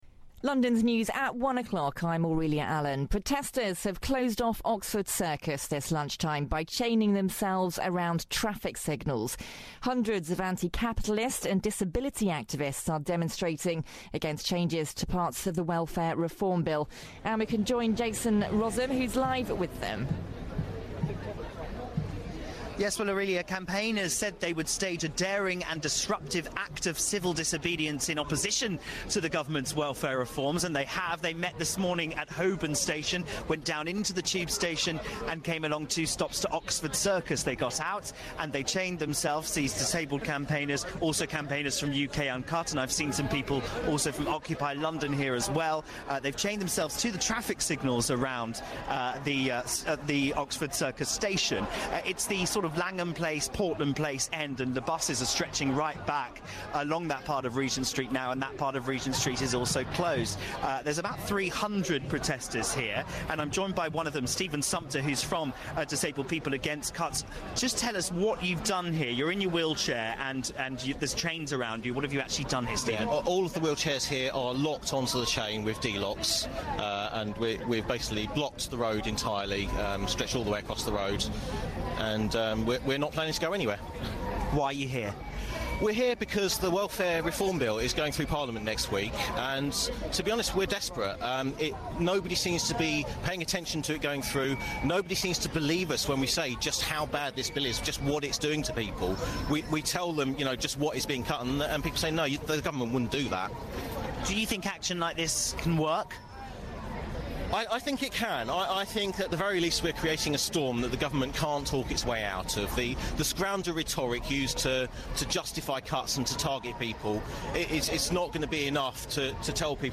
Interview live on BBC London radio